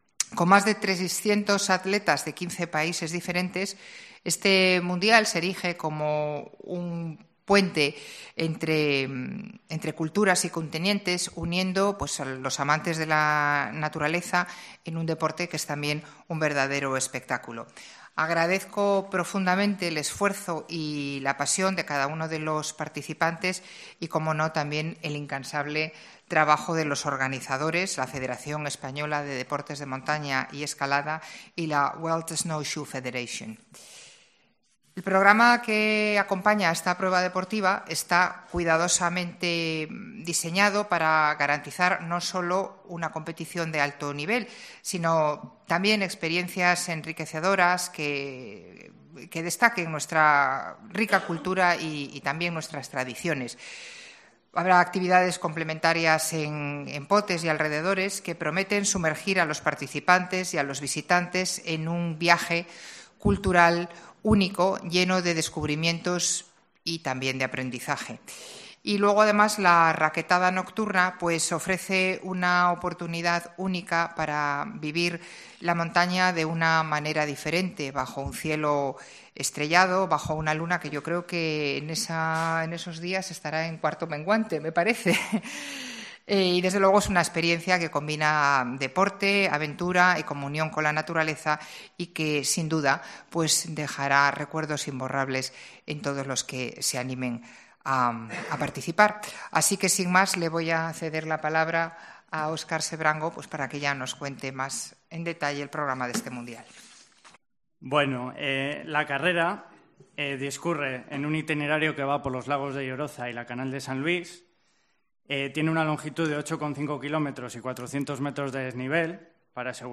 RUEDA DE PRENSA MUNDIAL RAQUETAS DE NIEVE